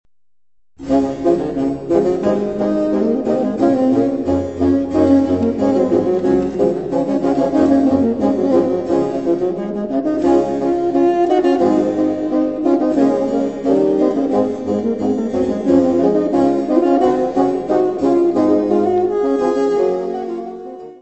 fagote
Music Category/Genre:  Classical Music
Le Phénix (Concerto nº1 pour quatre bassons en ré majeur)
Allegro.